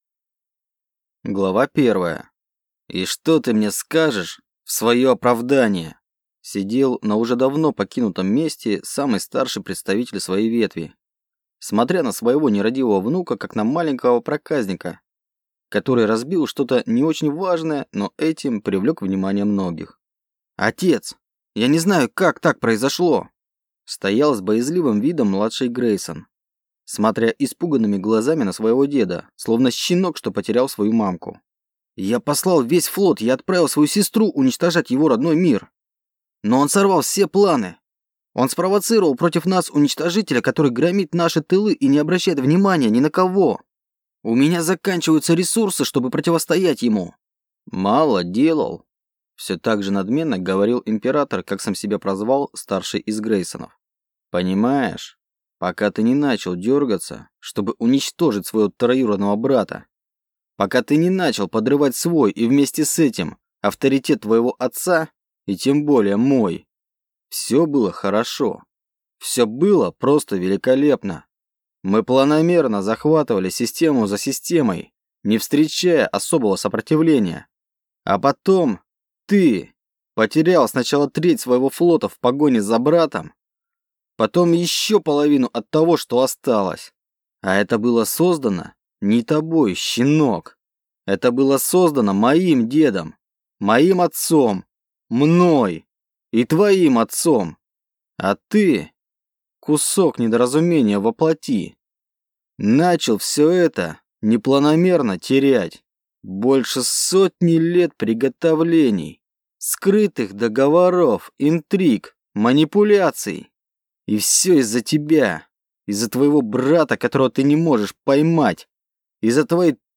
Последние Шаги 2023-11-25 17:42 ☘ Жанры книги: Аудиокниги про попаданцев А. Бредвик.